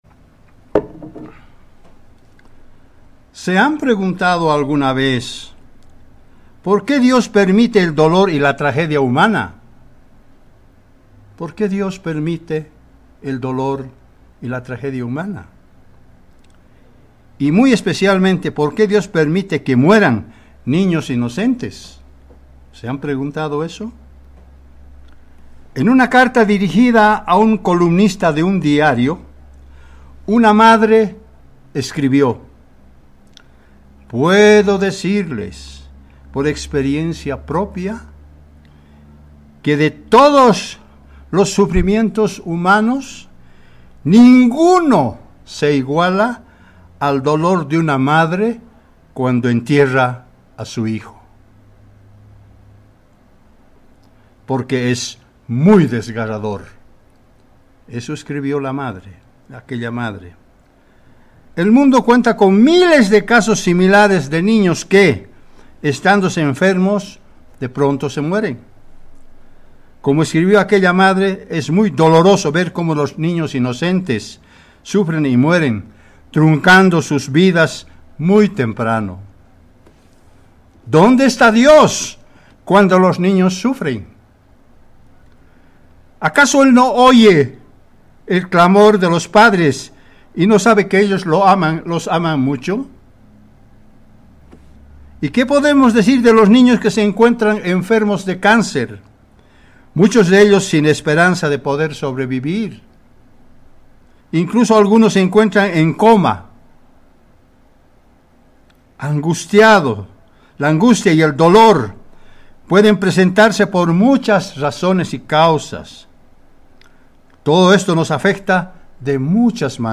En este sermón puede encontrar la respuesta.